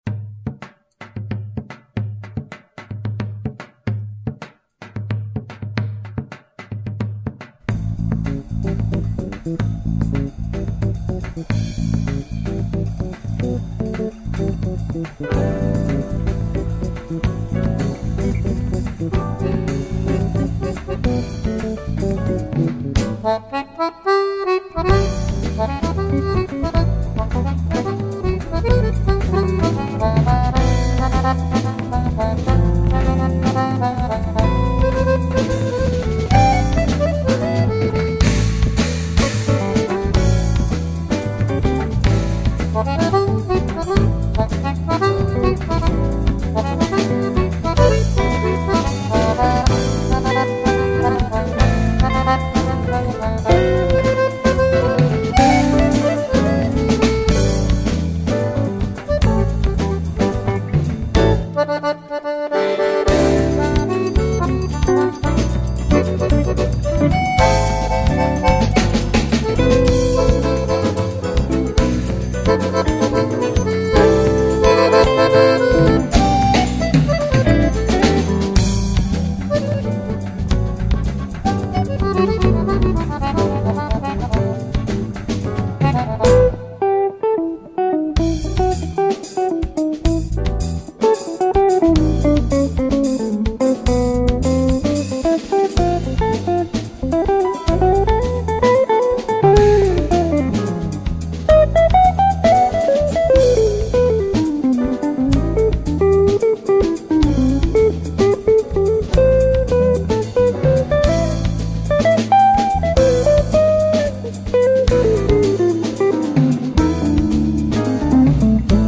275   05:05:00   Faixa:     Jazz
Baixo Elétrico 6
Teclados, Acoordeon
Guitarra
Bateria
Percussão